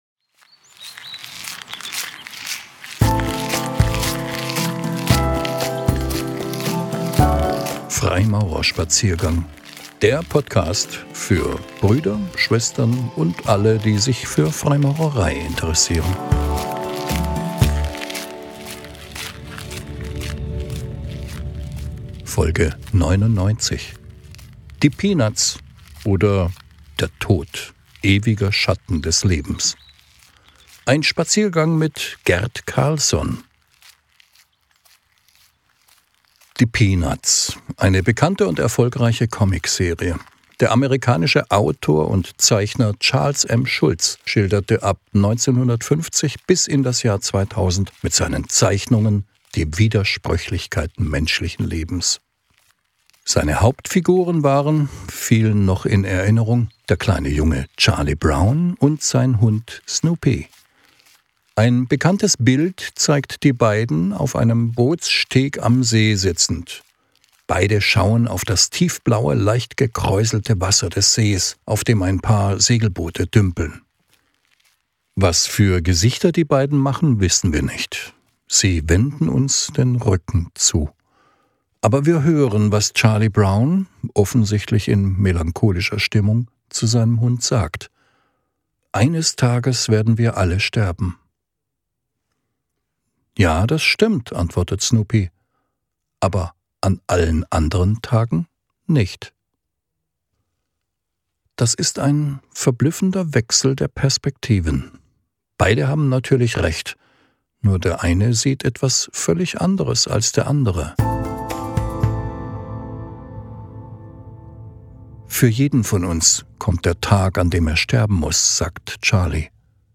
Ausgewählte "Zeichnungen" (Impulsvorträge) von Freimaurern.